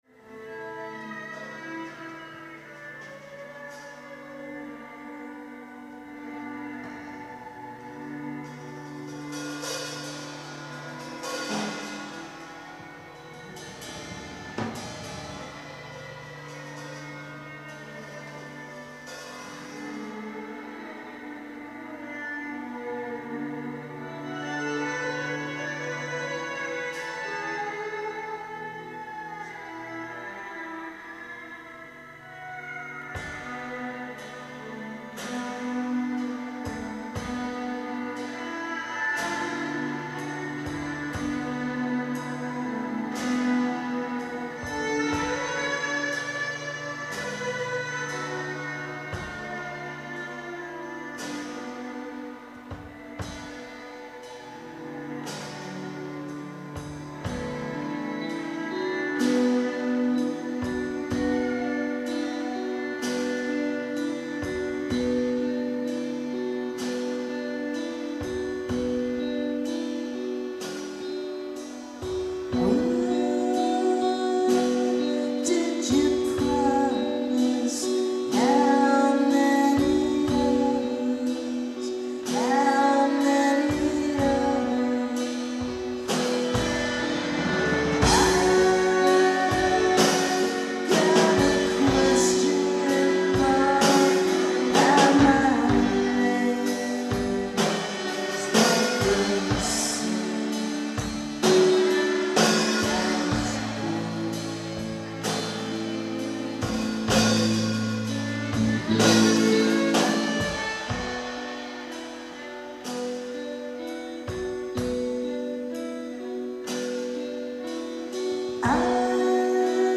Live at the Boston Museum of Fine Arts